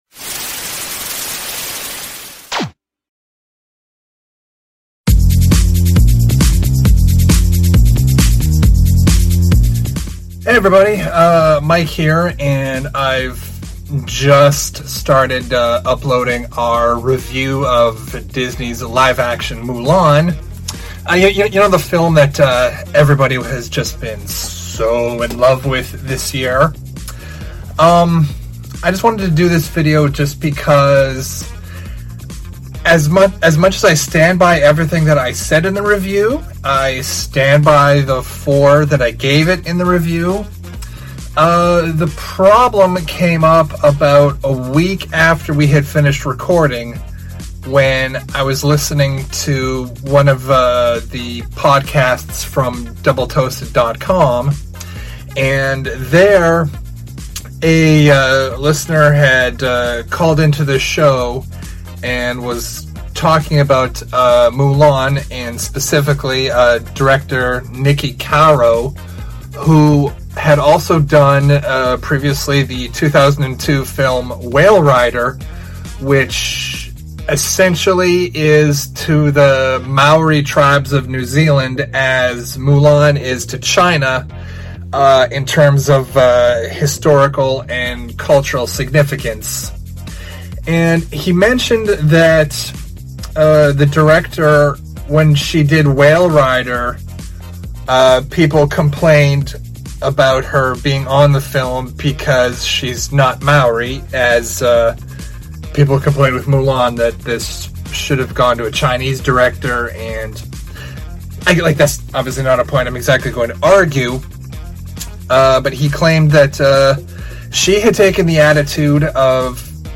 Recorded in Halifax, NS, Canada